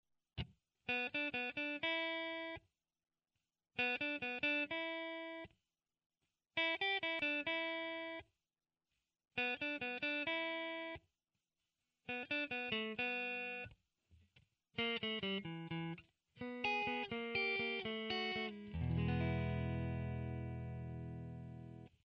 Beginners' blues [MP3]